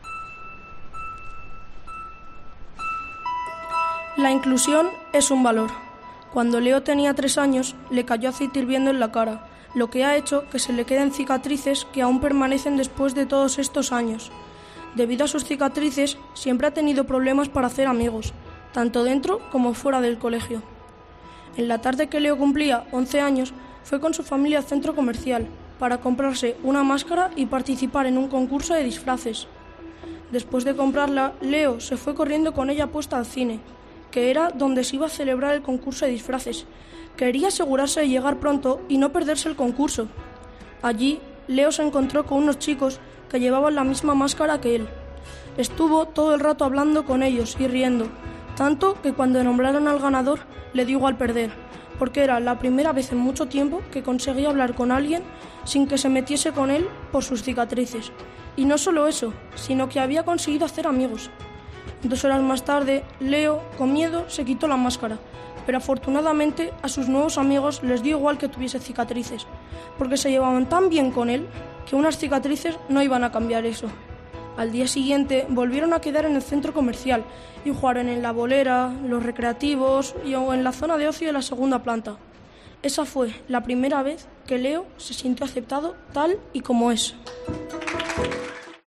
lee su historia